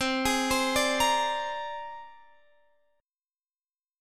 Listen to C7sus2#5 strummed